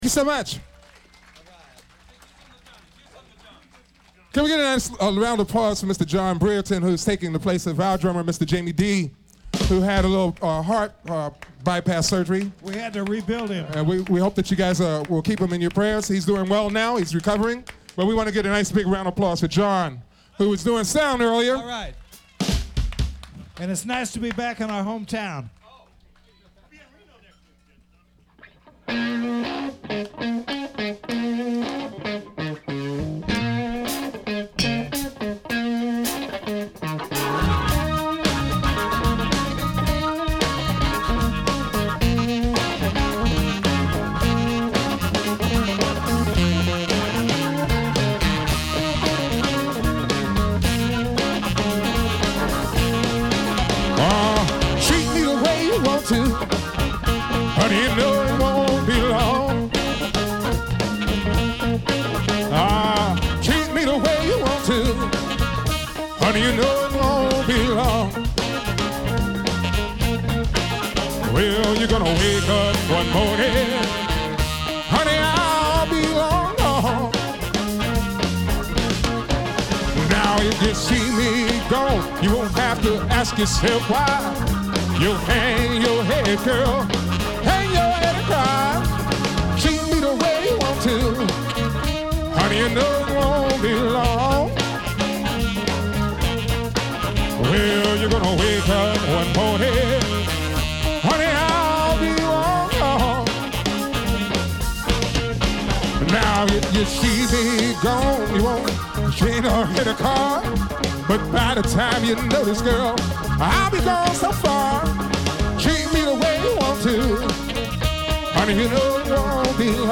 fill in show - live board recording: